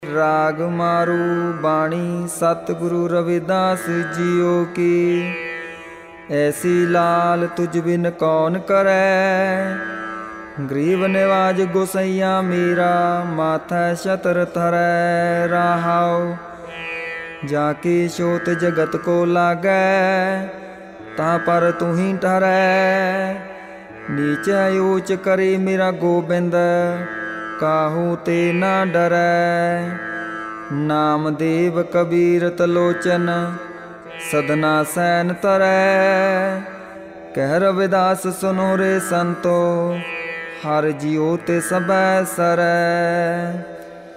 राग मारू बाणी रविदास जिउ की